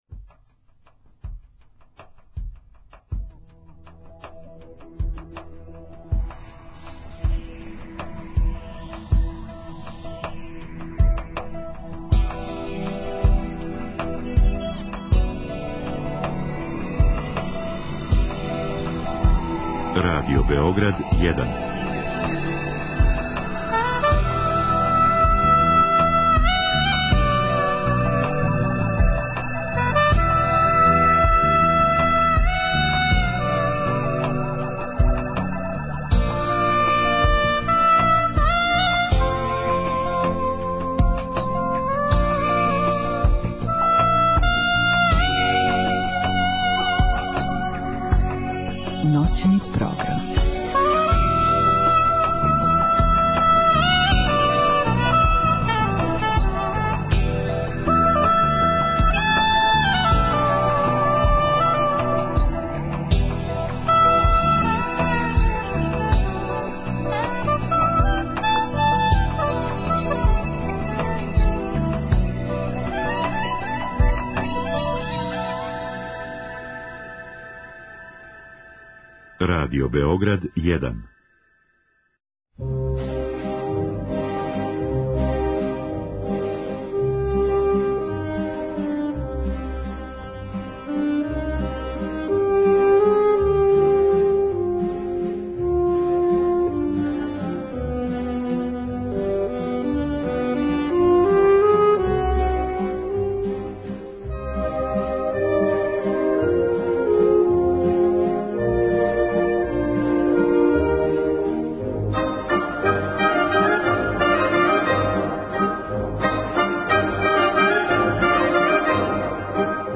Други и трећи сат посвећујемо манифестацији Piano Summer Fest, која се одржава у Врању до 20. августа. Поред репортаже, емитоваћемо и снимке одабраних концерата са овог фестивала.